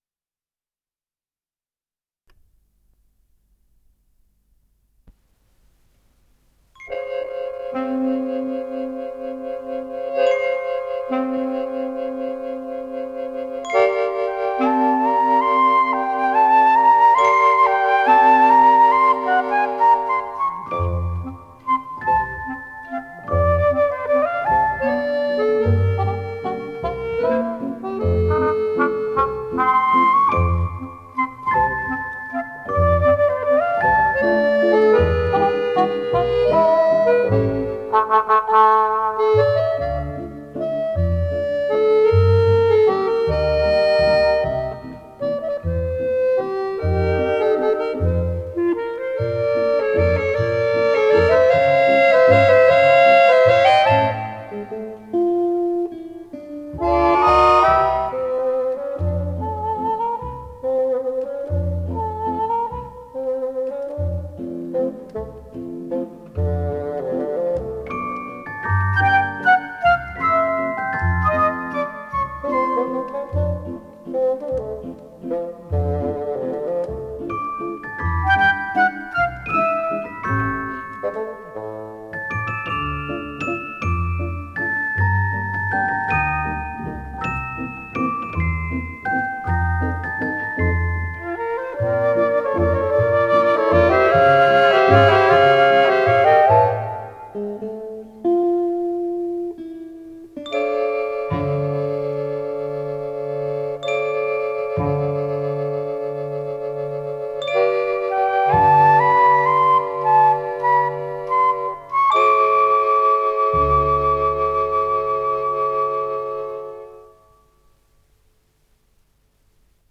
с профессиональной магнитной ленты
РедакцияМузыкальная детская